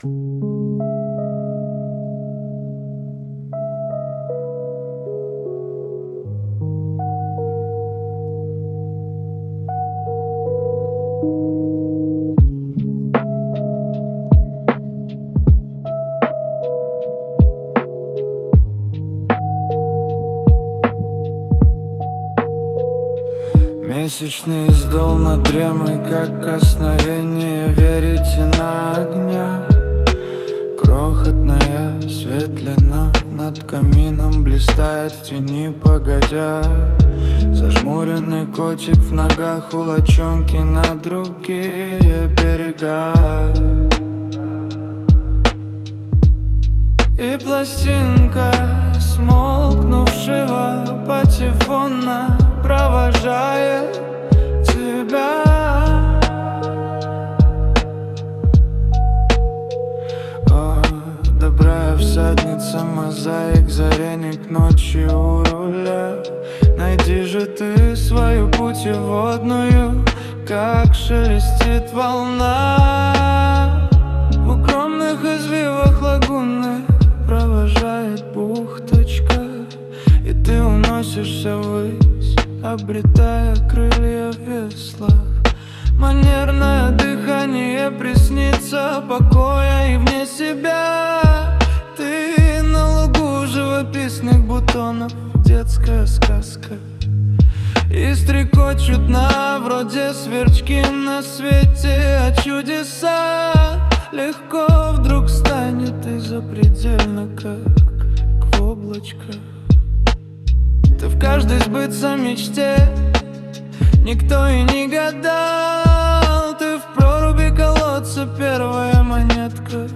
Музыка и исполнение принадлежит ИИ.
ТИП: Пісня
СТИЛЬОВІ ЖАНРИ: Романтичний